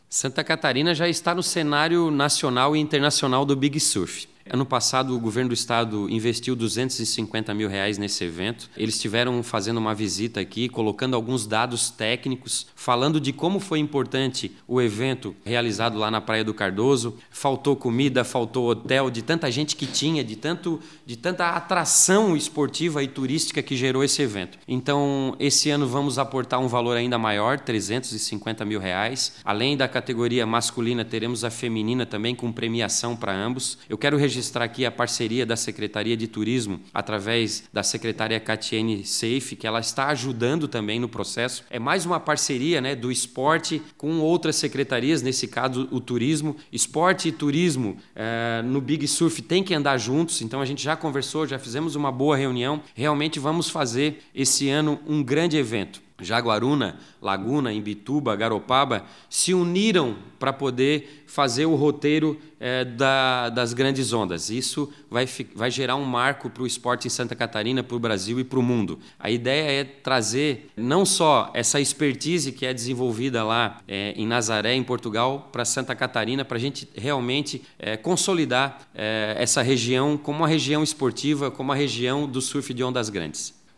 O presidente da Fesporte, Jeferson Batista, fala sobre o apoio da Fundação para o desenvolvimento do surf de ondas grandes em Santa Catarina: